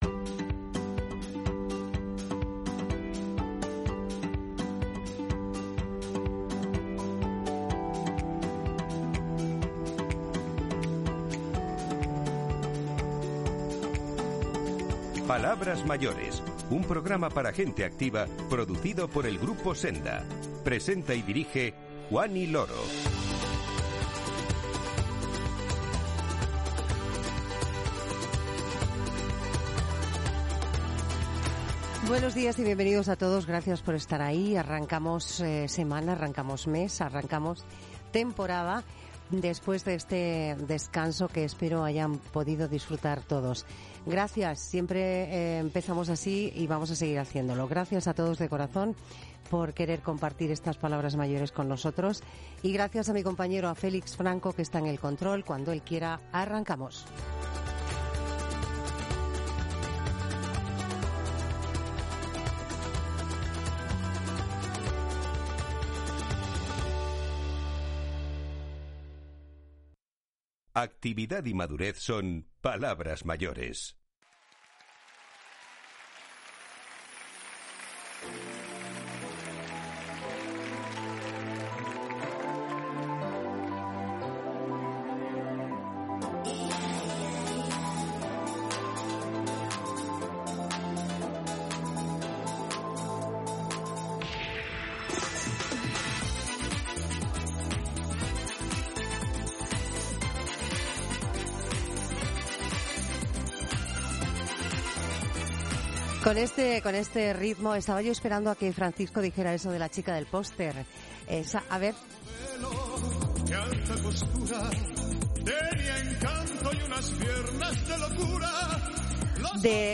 Con ellas charlamos en el programa para conocer qué buscan los sénior en el mundo de la moda.